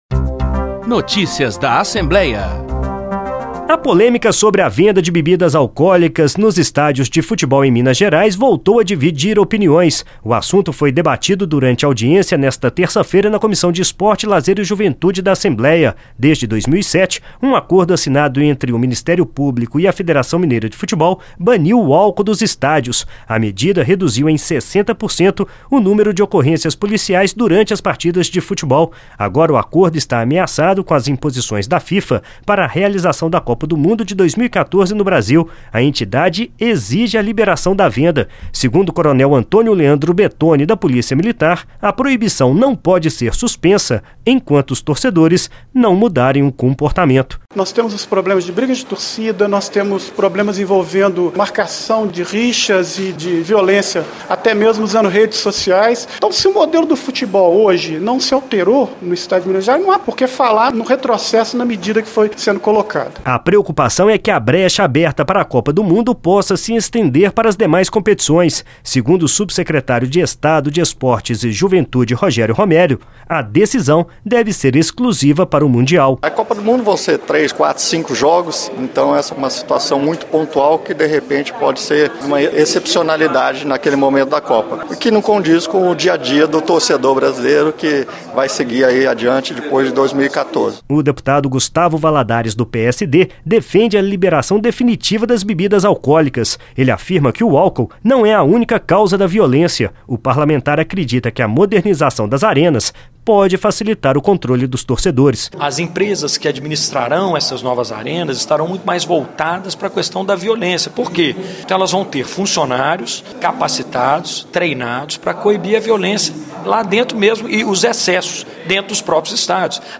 Parlamentares discutem a liberação do álcool nos estádios de futebol